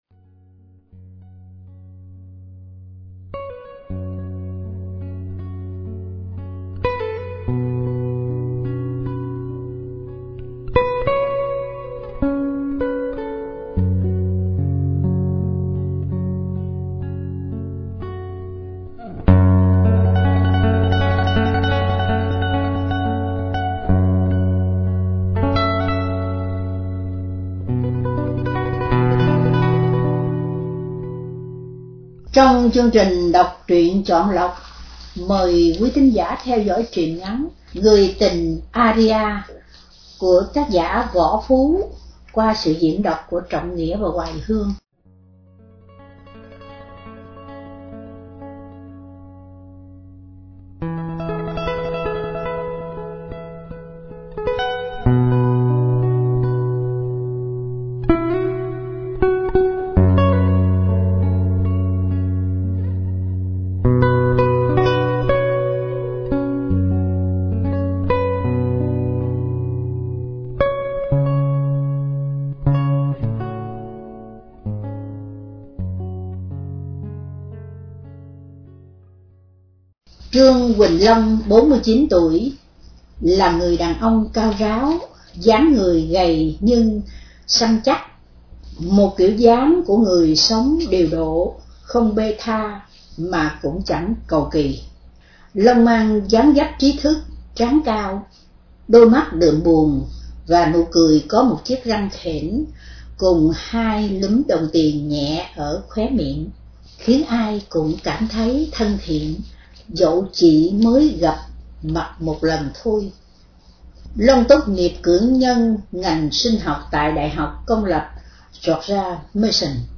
Đọc Truyện Chọn Lọc – Người Tình Aria- Phan – Radio Tiếng Nước Tôi San Diego